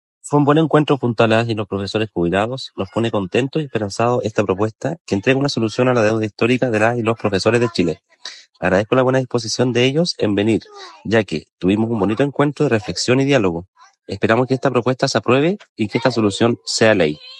Al respecto, el Seremi de Educación, Juan Eduardo Gómez, destacó el encuentro con los profesores jubilados, calificándolo como positivo y esperanzador, además, agradeció la disposición de los educadores para asistir y participar, destacando el carácter reflexivo y de diálogo de la reunión.